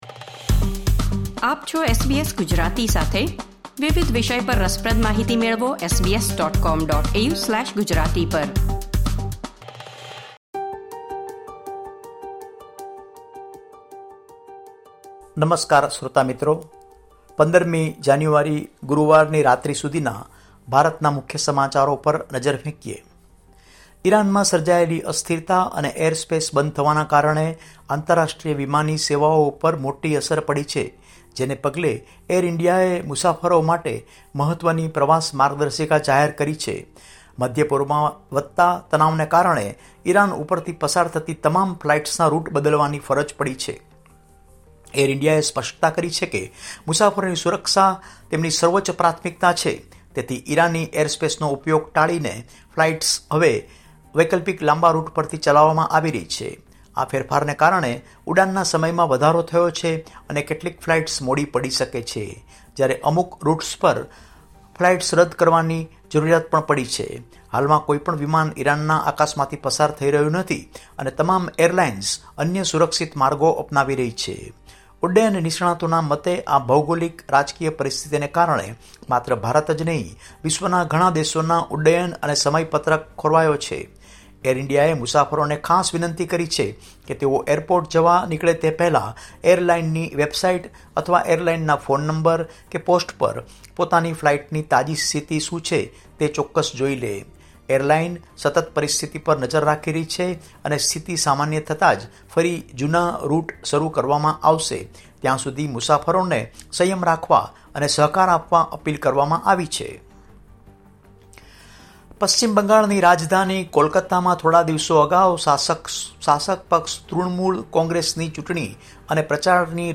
Listen to the latest news from India